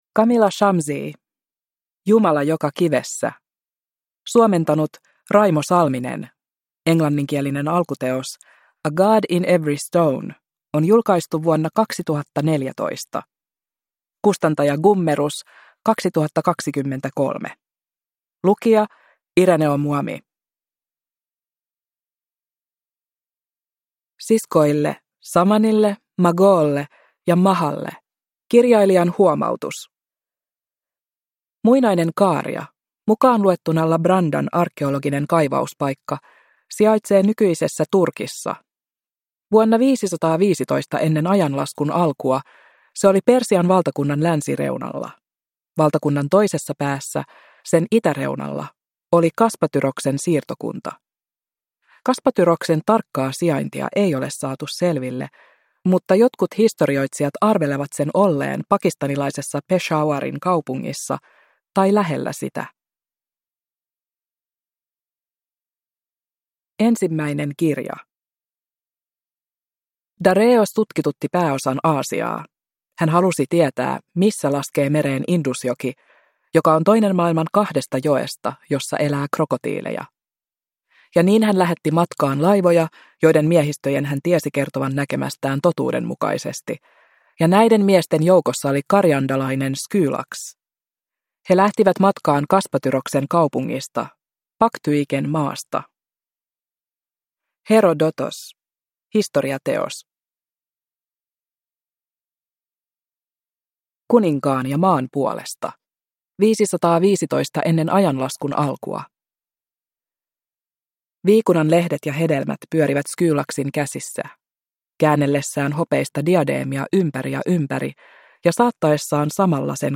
Jumala joka kivessä – Ljudbok – Laddas ner